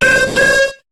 Cri de Xatu dans Pokémon HOME .